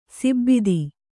♪ sibbidi